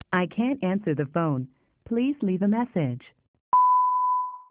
EnglishAnswer.amr